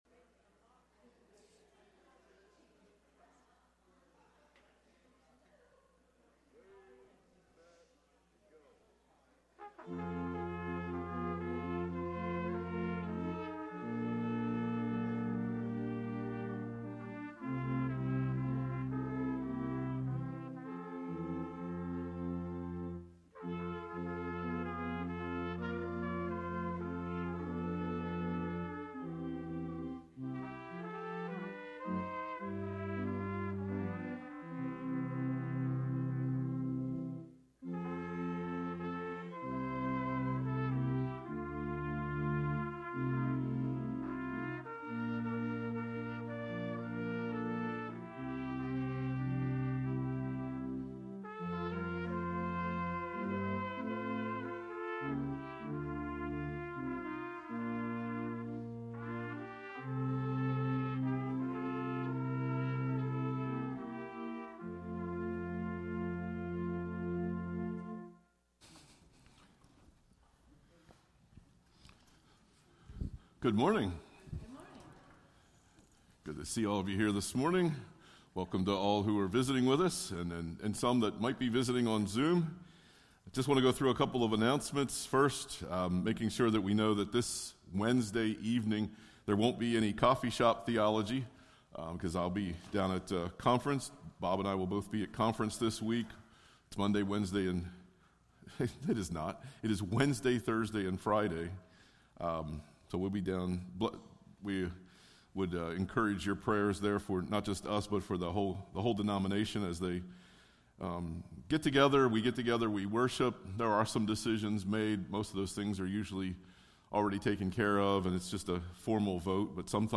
Sermons by Palmyra First EC Church